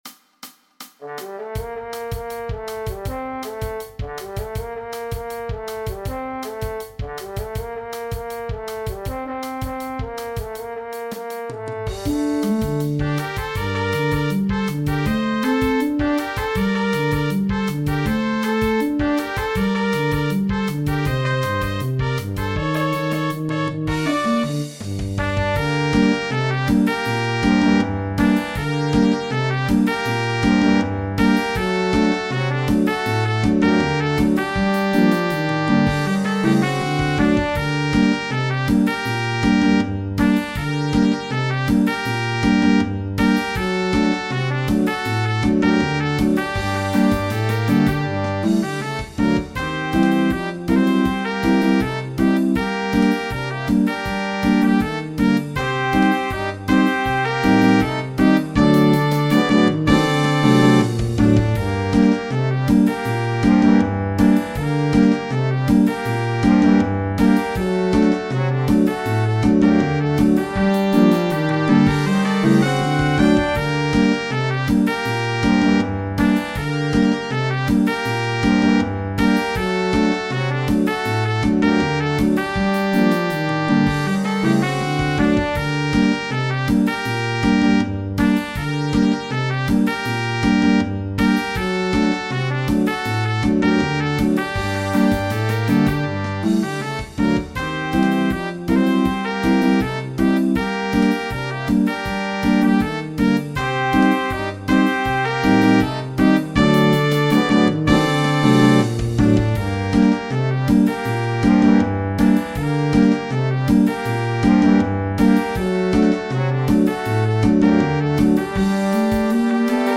Le klezmer est devenu ici plus "jazzy"… mais qu’importe.